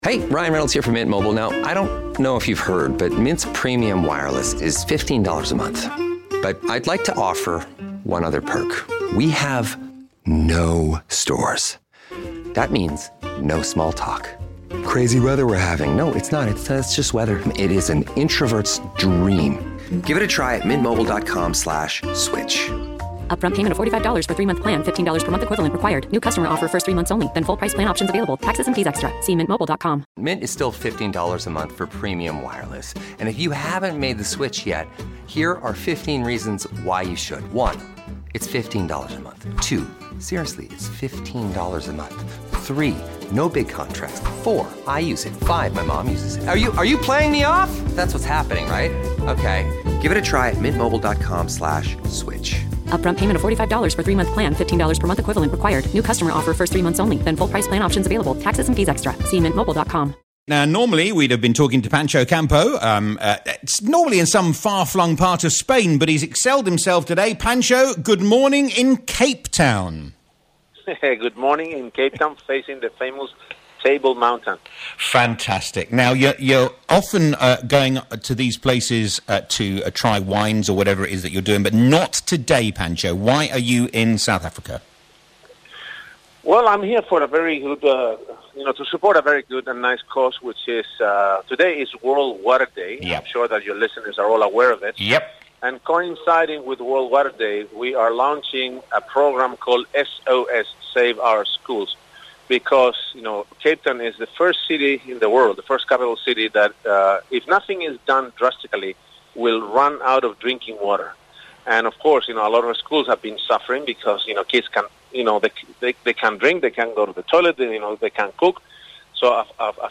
live from Cape Town